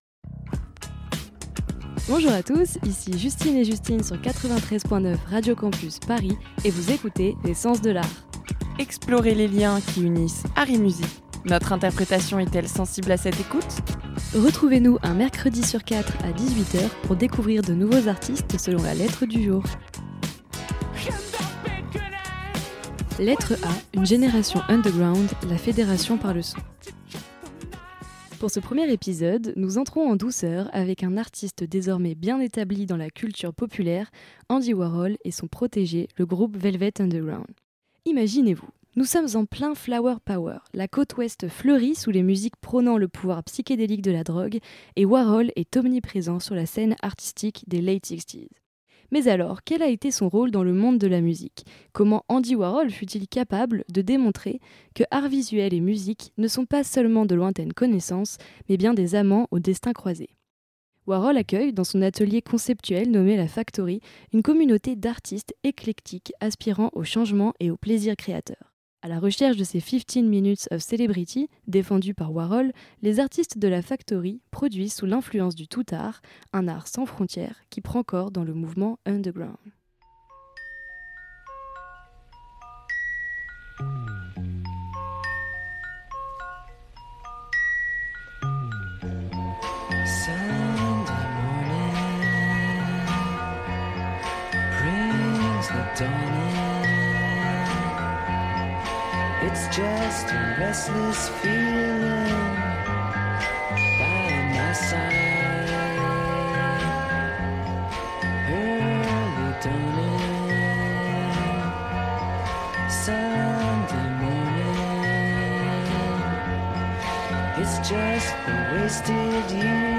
Type Musicale Culture Pop & Rock